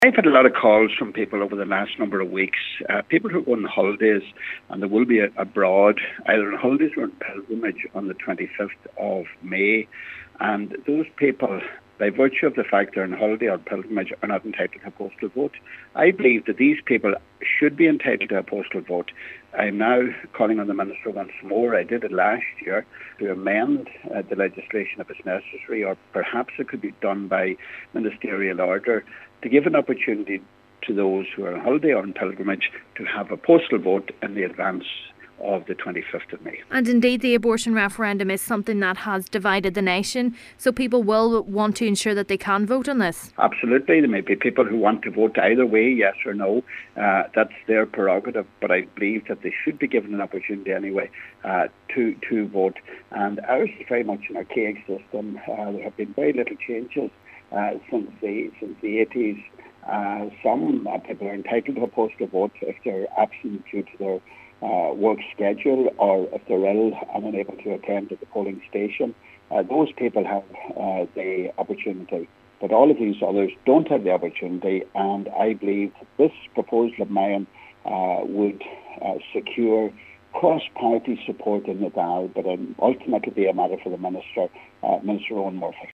He says he will be calling on Minister Eoghan Murphy once again, to amend the legislation: